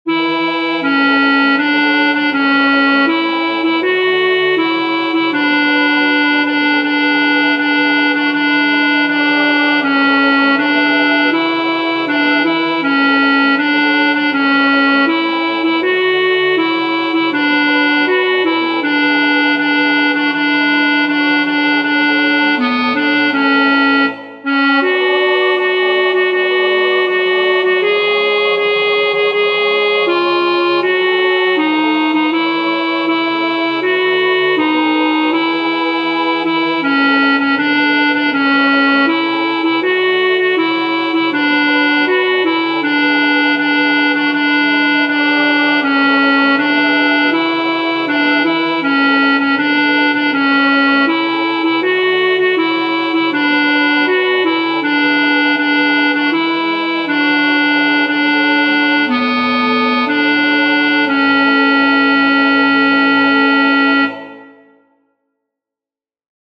Esta canción popular tiene una estructura muy sencilla, tipo A-B-A’.
El tempo aparece indicado como Moderato.
o-sari-mares-alto.mp3